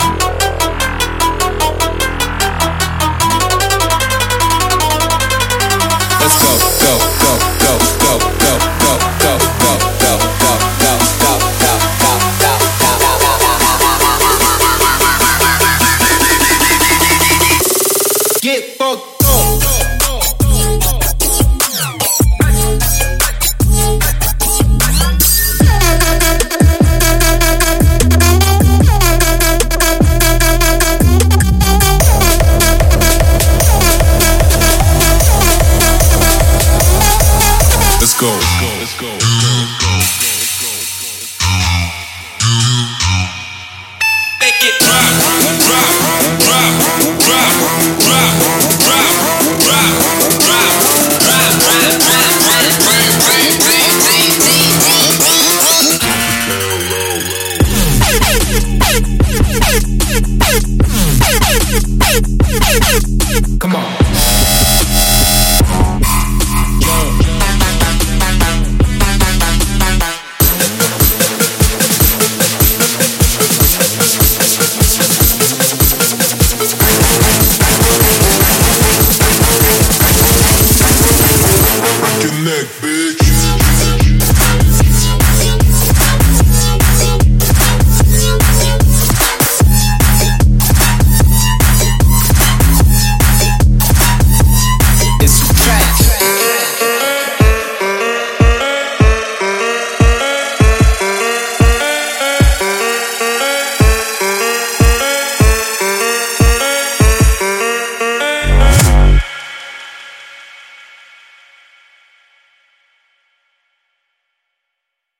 通过他的重击鼓，响亮的打击乐，猛烈的立管，高强度的合成器和光滑的切碎或口语人声样本集达到新的维度。